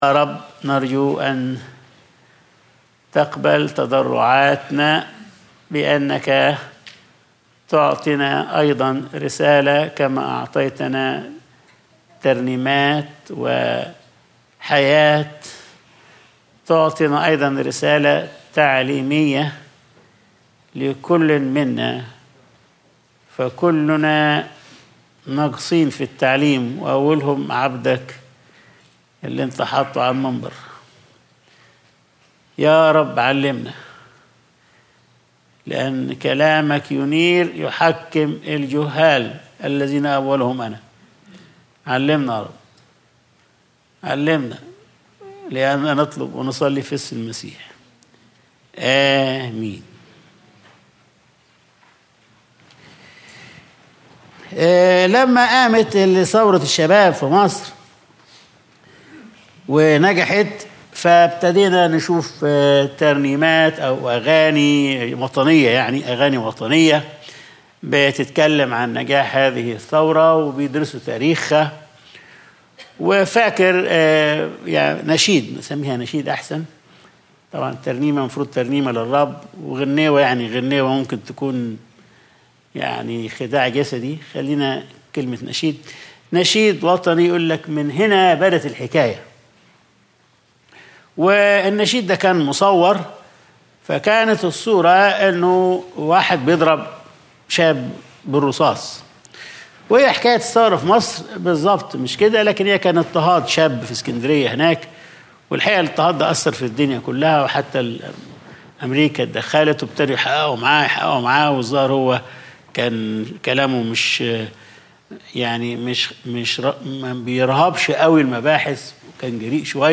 Sunday Service | الجنة .. الجامعة .. القيامة